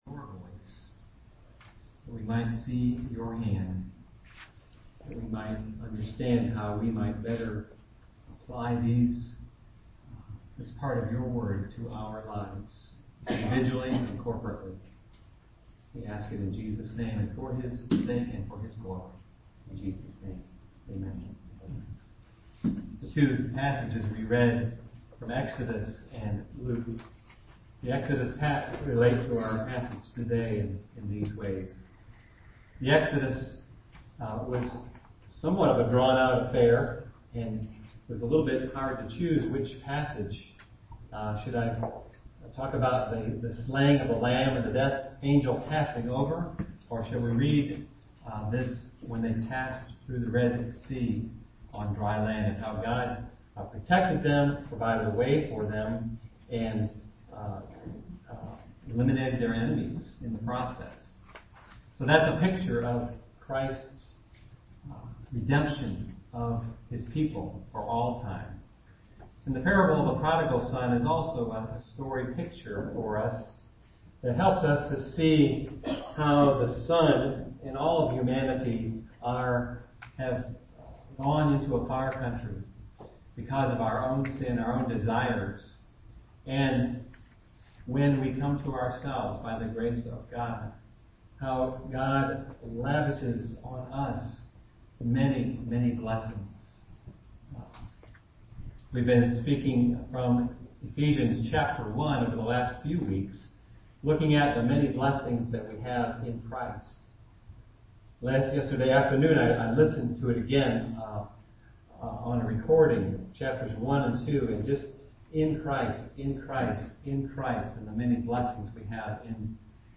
Home › Sermons › Sermons by Year › 2012 › Exalted in Christ (Ephesians 2:1-6)